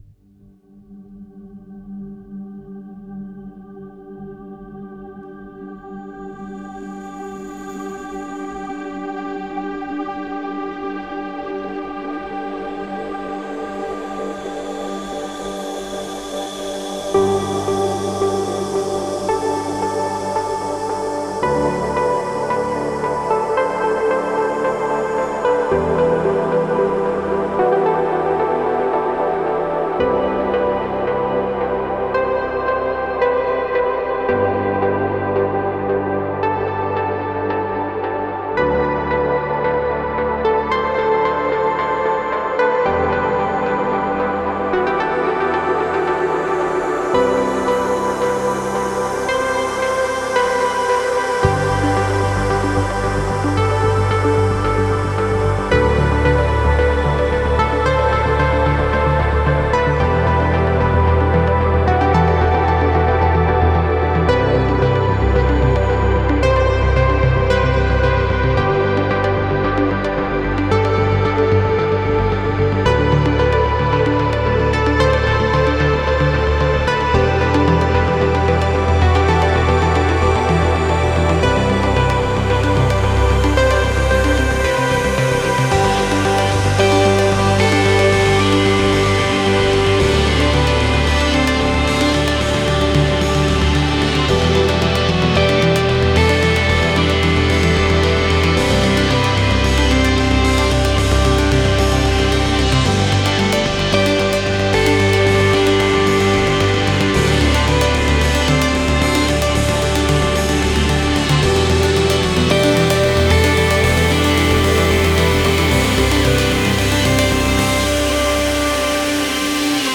Стиль: Chillout / Lounge